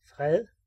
Ääntäminen
France: IPA: [pɛ]